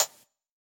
UHH_ElectroHatD_Hit-08.wav